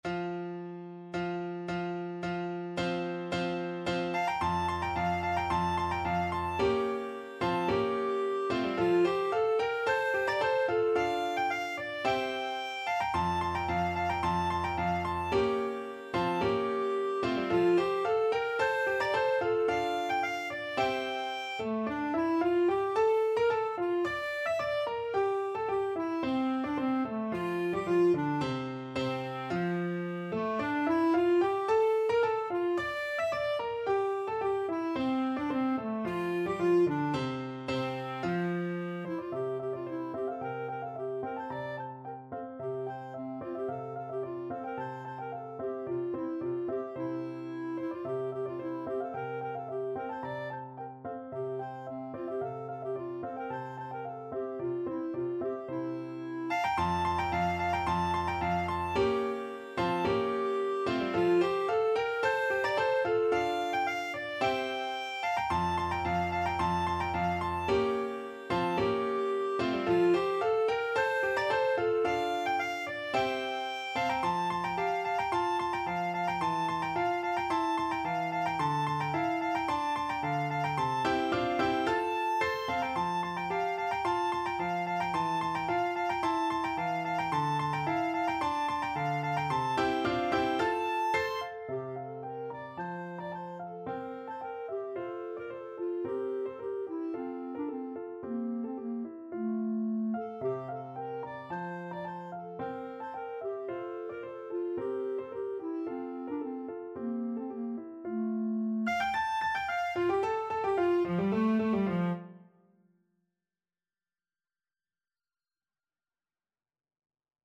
Classical Mendelssohn, Felix Bergamask from A Midsummer Night's Dream (A Dance of Clowns) Clarinet version
Clarinet
= 110 Allegro di molto (View more music marked Allegro)
F major (Sounding Pitch) G major (Clarinet in Bb) (View more F major Music for Clarinet )
2/2 (View more 2/2 Music)
Classical (View more Classical Clarinet Music)